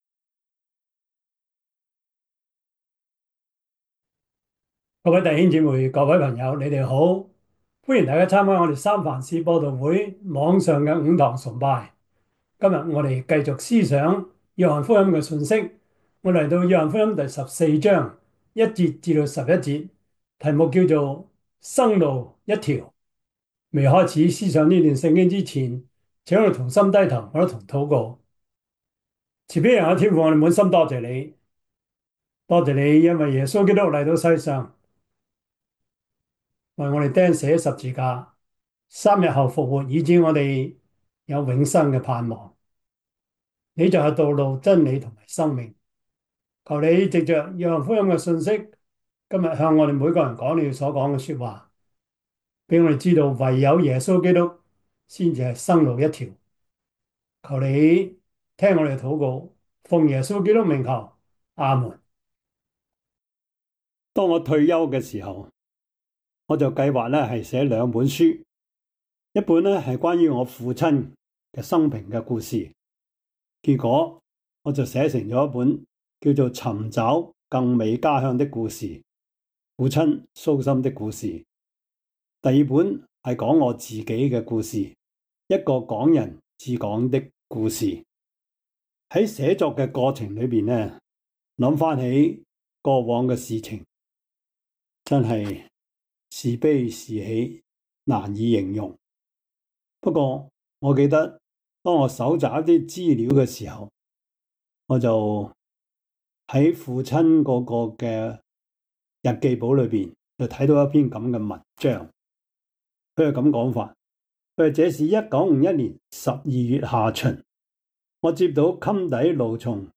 約翰福音 14:1-11 Service Type: 主日崇拜 約翰福音 14:1-11 Chinese Union Version
Topics: 主日證道 « 要警醒！